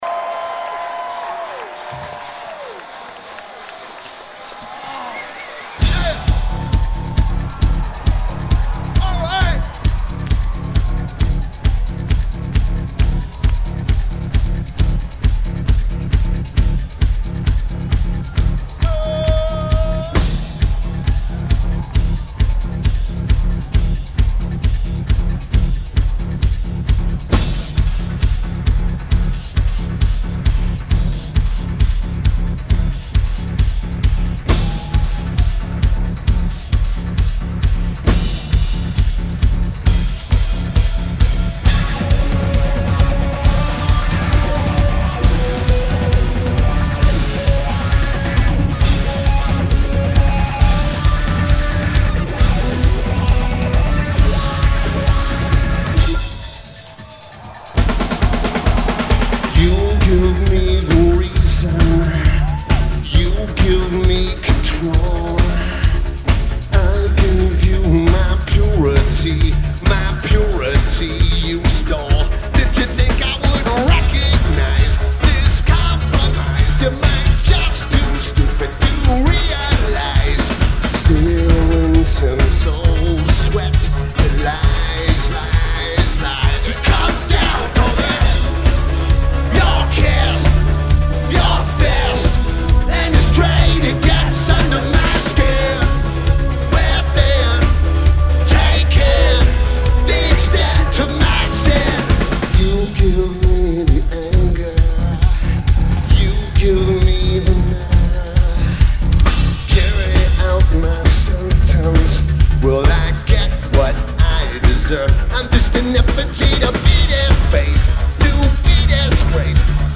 The Palladium
Los Angeles, CA United States